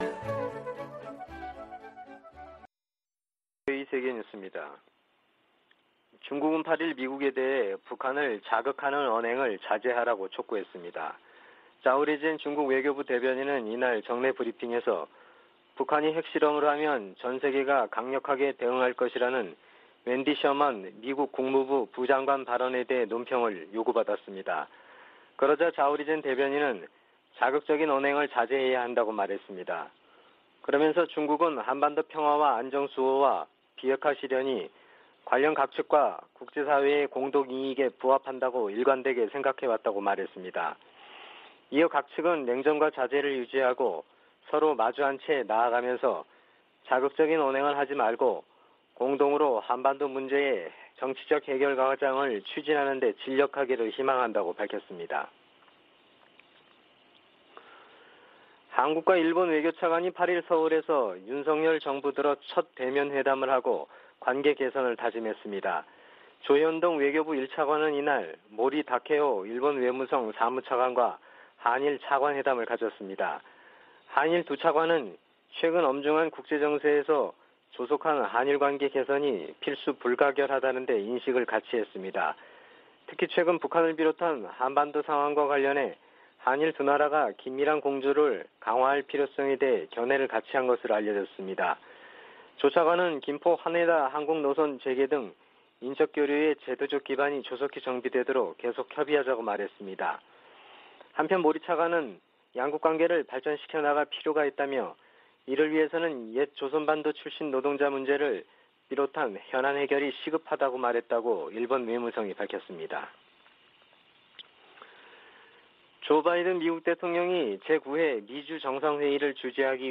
VOA 한국어 아침 뉴스 프로그램 '워싱턴 뉴스 광장' 2022년 6월 9일 방송입니다. 미·한·일 외교차관들이 서울에서 만나 북한의 핵과 미사일 위협의 고도화에 대응해 안보협력을 강화하기로 했습니다. 북한이 풍계리에서 7차 핵실험 준비를 마쳤으며 언제라도 실험에 나설 수 있다고 성 김 미 대북특별대표가 밝혔습니다. 북한이 코로나 사태에 국제사회의 지원을 받으려면 현장 접근과 물자 반입, 국제직원 상주 등을 허용해야 한다고 유럽연합이 지적했습니다.